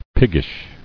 [pig·gish]